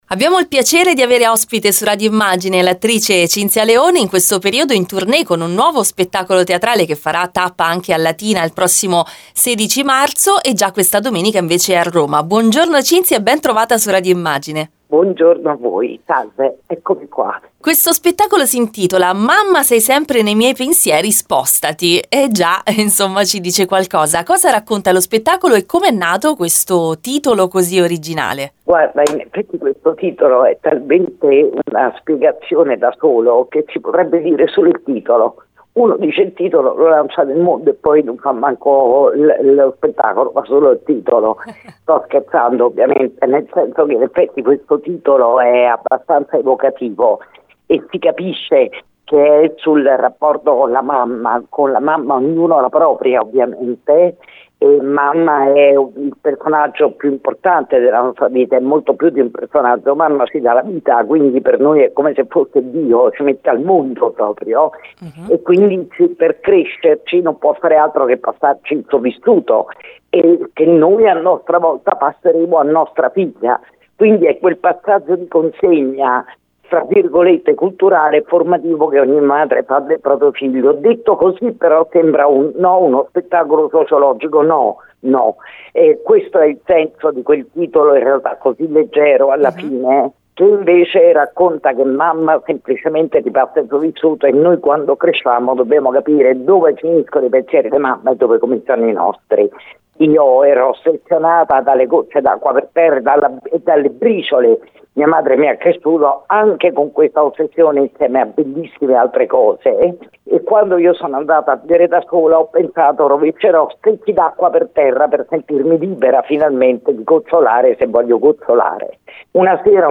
L’intervista a Cinza Leone